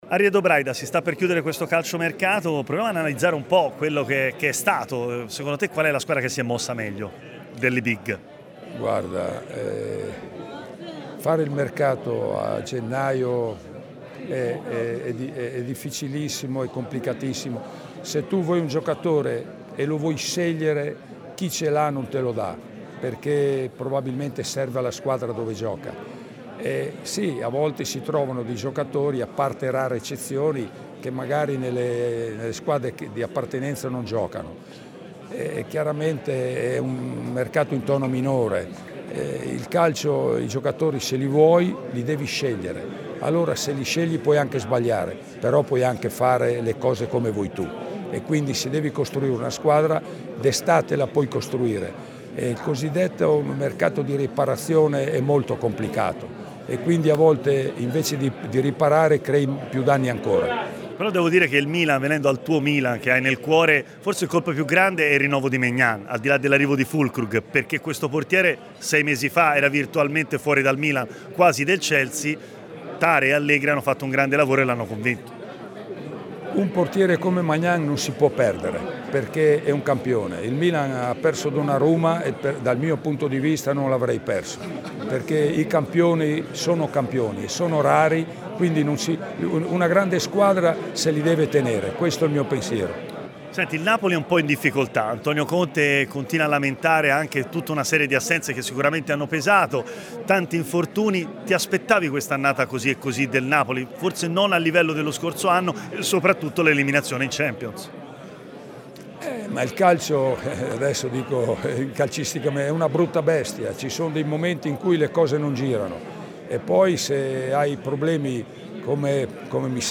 Oggi alle 21:00Le Interviste
Ariedo Braida ha parlato ai microfoni di Tmw Radio nel corso della cerimonia di chiusura del mercato invernale.